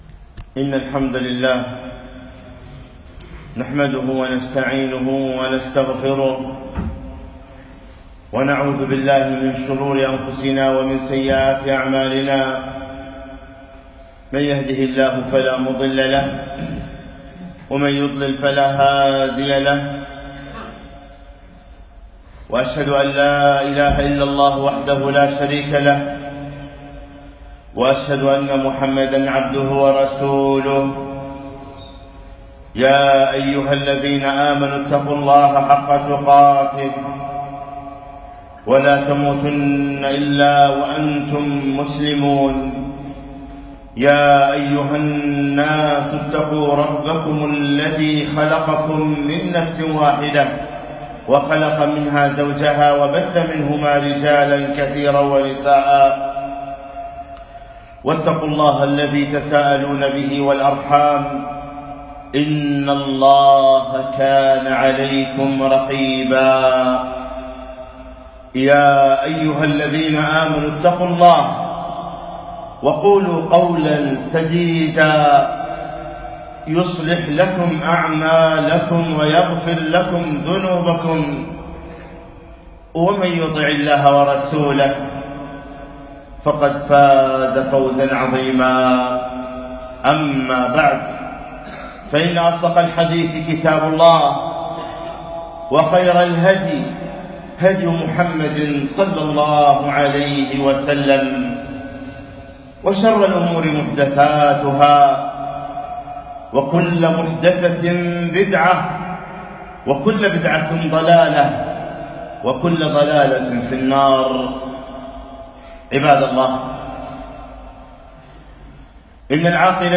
خطبة - أن مردنا إلى الله